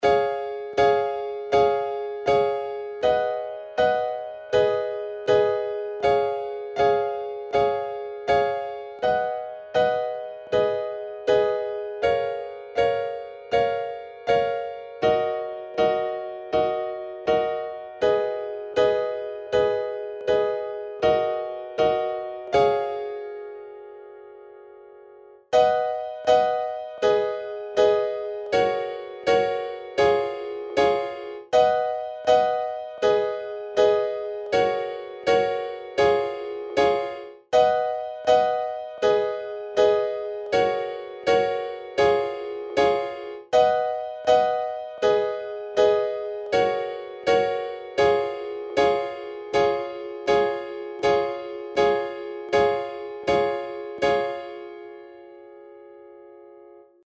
Ich kann nur grob Noten lesen, und weiss ansich nichts ueber Musiktherorie Was ich bisher rausgefunden habe: 1. Db (klingt bei mir aber irgendwie anders als in der Audio) 2. G#m (klingt richtig wie ich es spiele) 3. C# (auch hier klingt es irgendwie anders) 4. Bbm (fast ok) 5. Ebm (fast ok aber ende des ersten Parts ist der Akkord irgendwie veraendert) Soweit ich weiss kann Cubase 12 die Akkorde rauslesen?
Vielen Dank schon im Vorraus fuer eure Hilfe PS:. Das tempo ist 80BPM Anhänge piano chords.mp3 889,8 KB